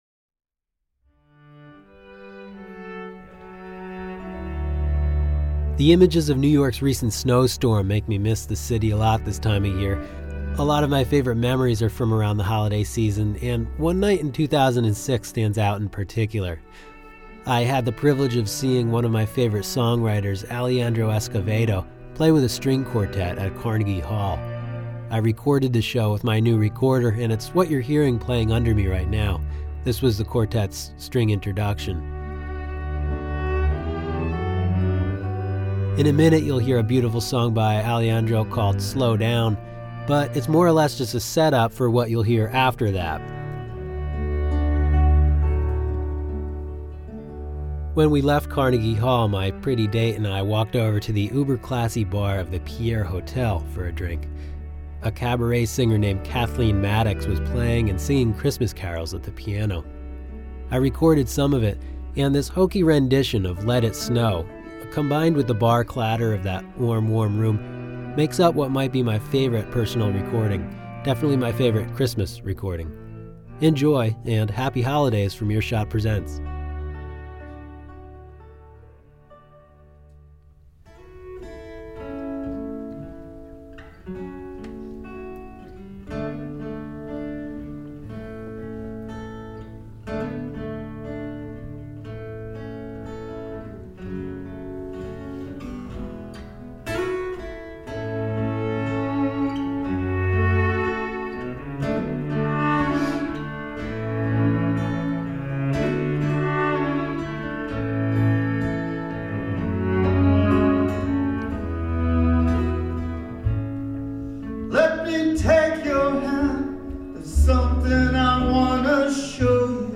In 2006, I had the privilege of seeing Alejandro Escovedo play with a string quartet at Carnegie Hall. This podcast from 2009 recounts the evening, when I recorded the show with a new Edirol hard-disk recorder I’d purchased a few days earlier.